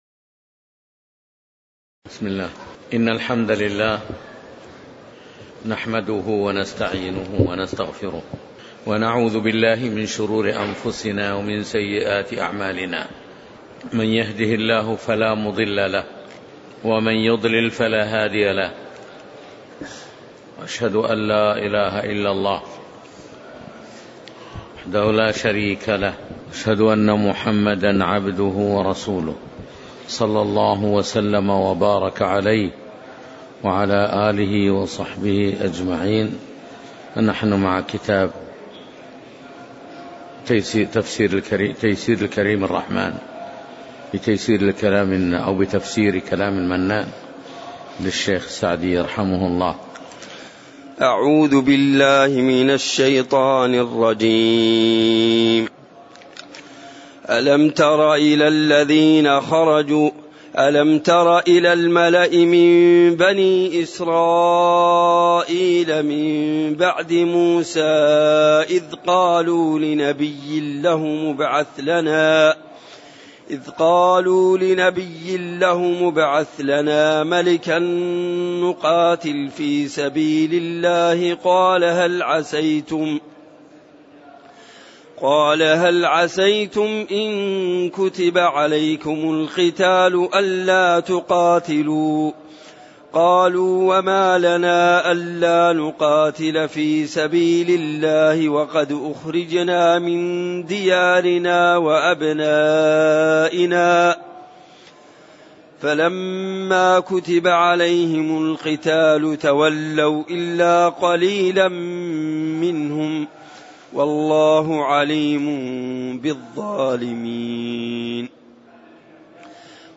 تاريخ النشر ١٧ جمادى الأولى ١٤٣٩ هـ المكان: المسجد النبوي الشيخ